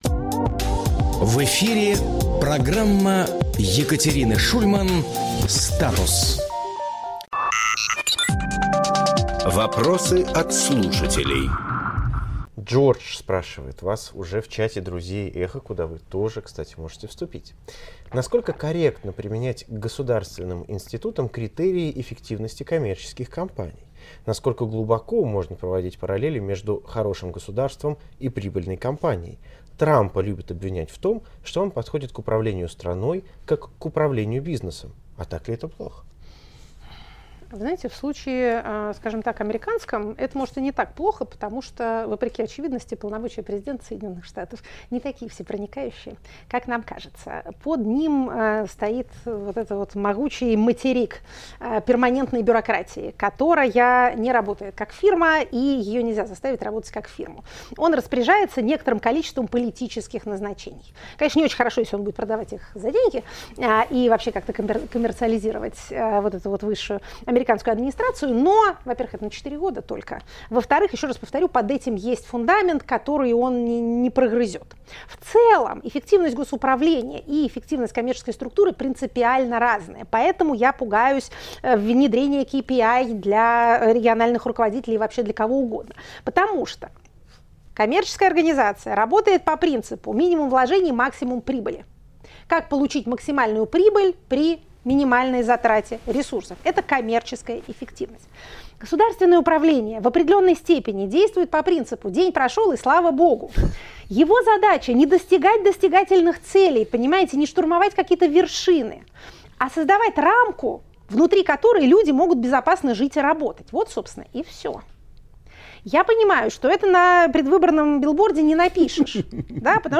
Фрагмент эфира от 07.01